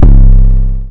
WV [808] plugg.wav